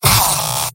Robot-filtered lines from MvM. This is an audio clip from the game Team Fortress 2 .
Engineer_mvm_painsharp06.mp3